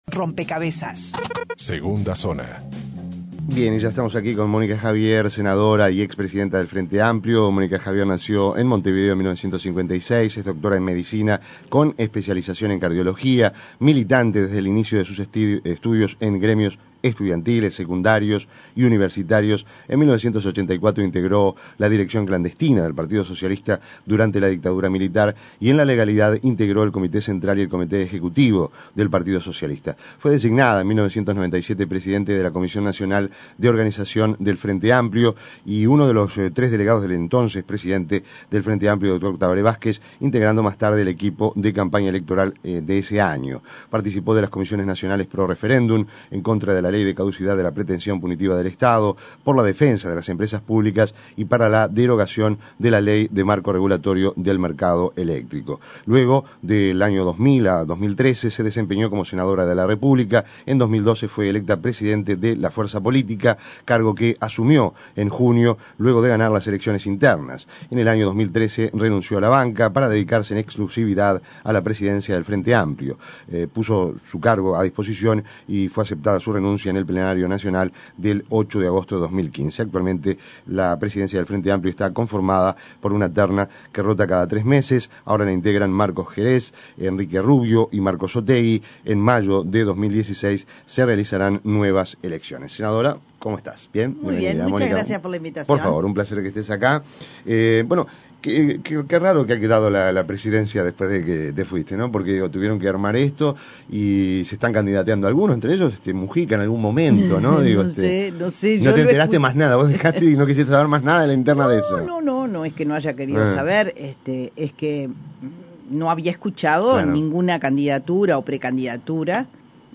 Descargar Audio no soportado Entrevista a Monica Xavier Ver video completo